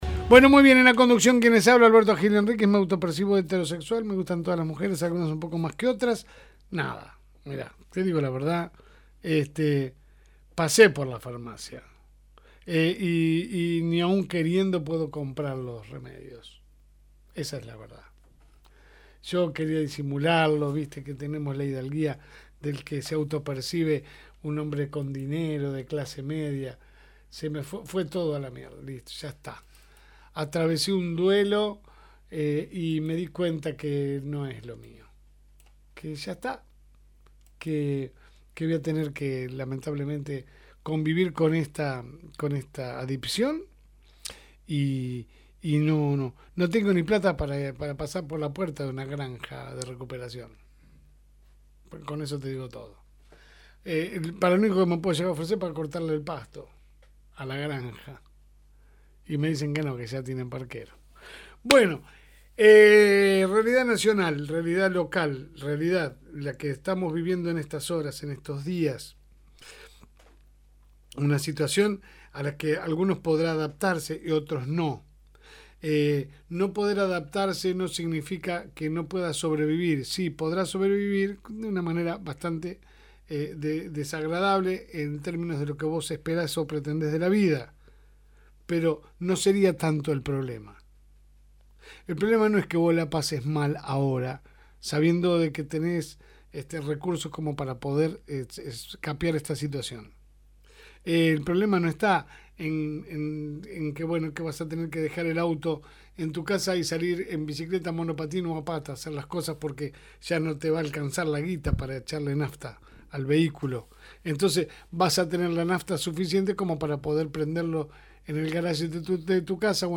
En La Segunda Mañana que sale de lunes a viernes de 10 a 12 hs por el aire de FM Reencuentro, tratamos de analizar la realidad Argentina a nivel local